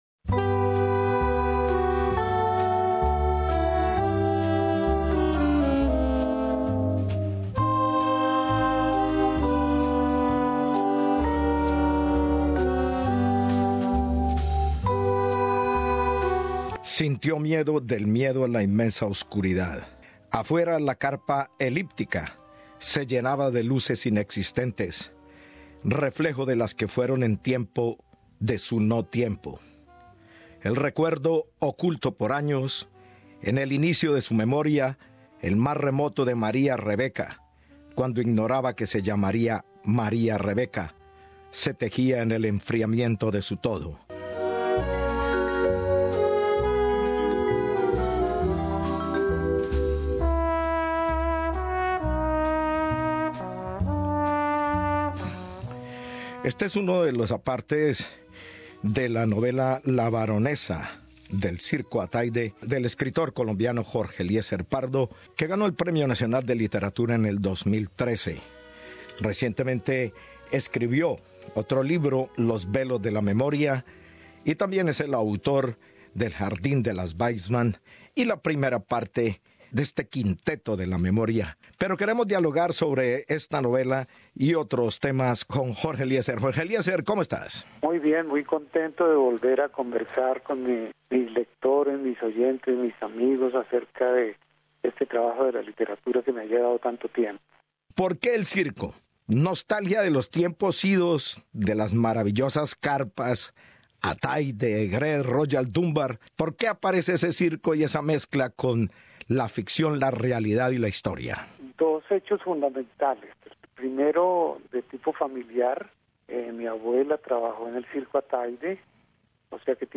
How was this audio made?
También escuchamos un fragmento de la novela: La baronesa del Circo Atayde